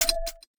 UIClick_Soft Tonal 01.wav